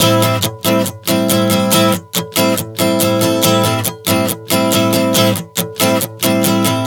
Strum 140 Am 05.wav